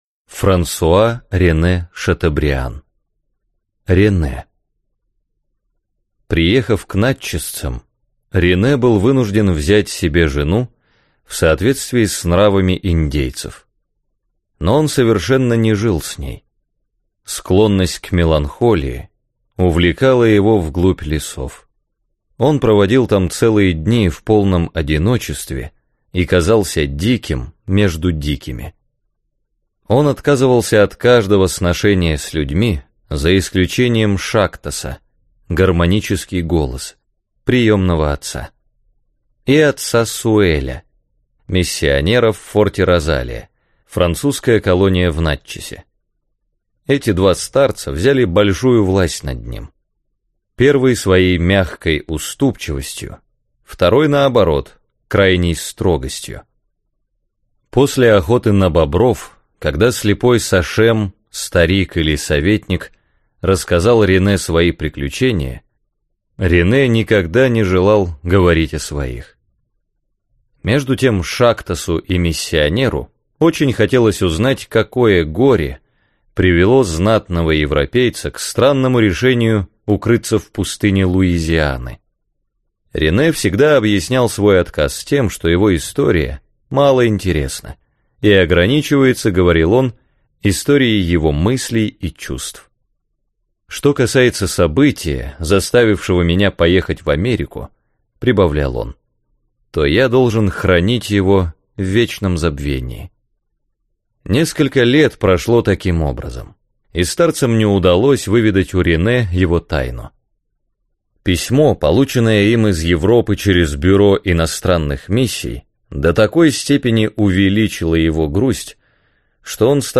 Аудиокнига Рене | Библиотека аудиокниг